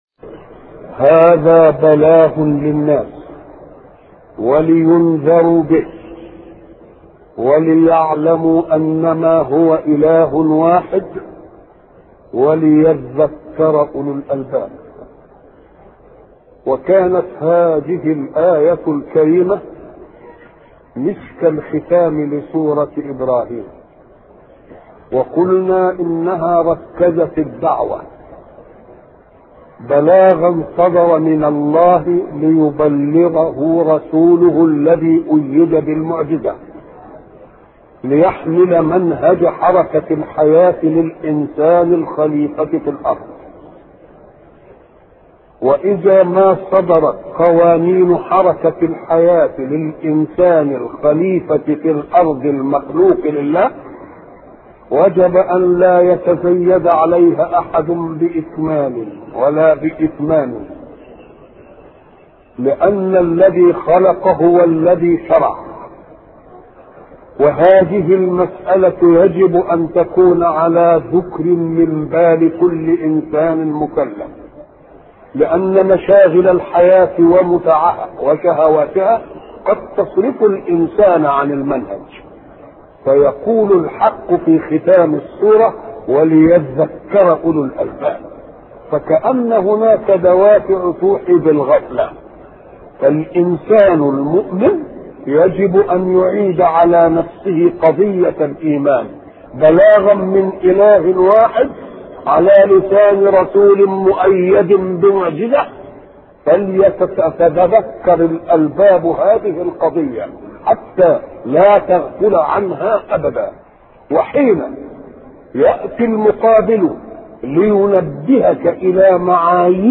أرشيف الإسلام - أرشيف صوتي لدروس وخطب ومحاضرات الشيخ محمد متولي الشعراوي